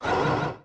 Sfx Secret Blocks Fade Sound Effect
sfx-secret-blocks-fade.mp3